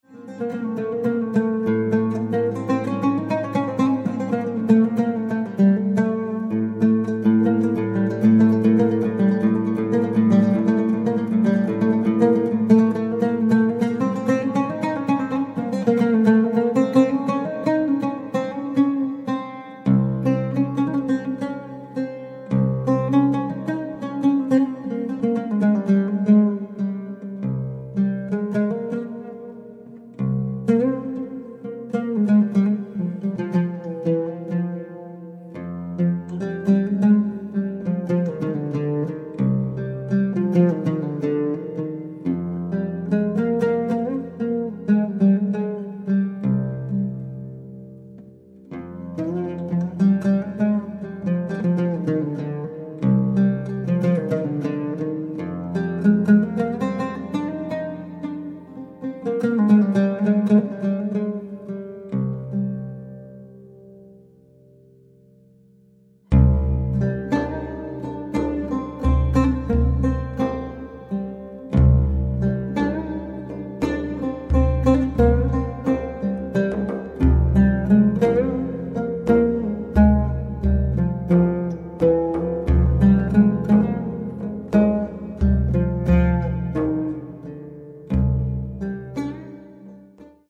composer, lute & oud player from Japan
Contemporary
Oriental , Oud